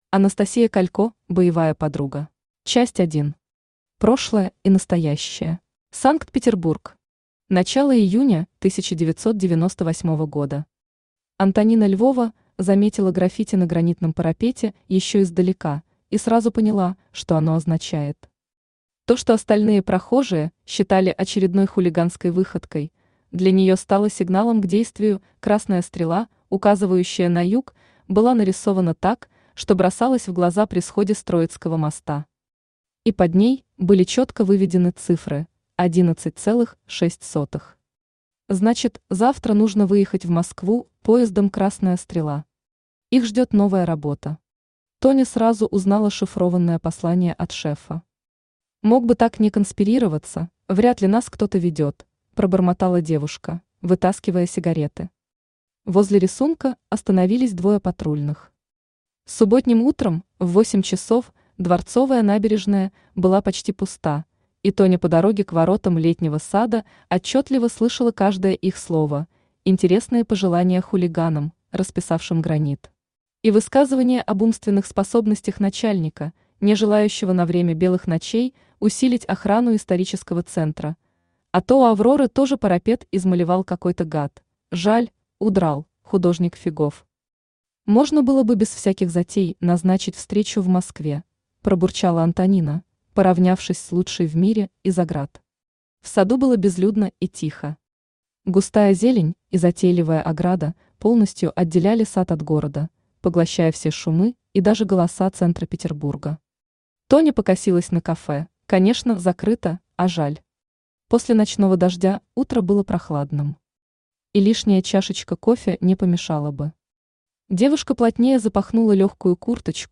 Аудиокнига Боевая подруга | Библиотека аудиокниг
Aудиокнига Боевая подруга Автор Анастасия Александровна Калько Читает аудиокнигу Авточтец ЛитРес.